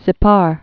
(sĭ-pär)